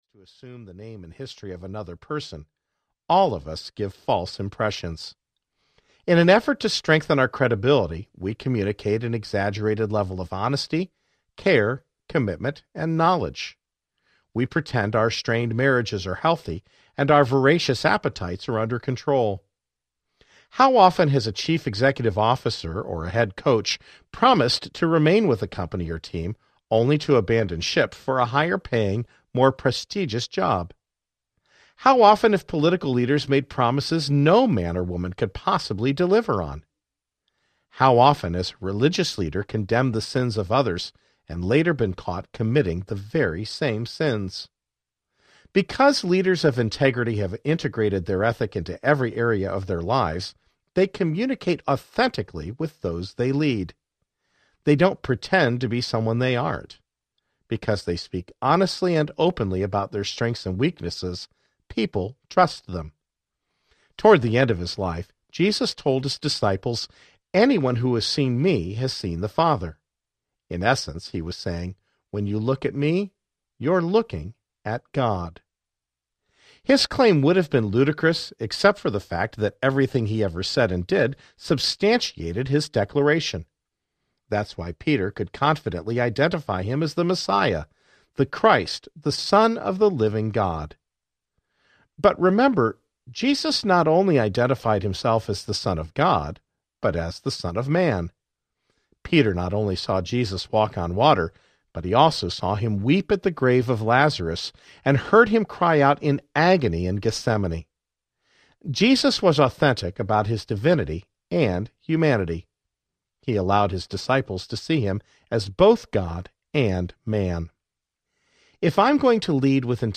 Awaken the Leader Within Audiobook
5.75 Hrs. – Unabridged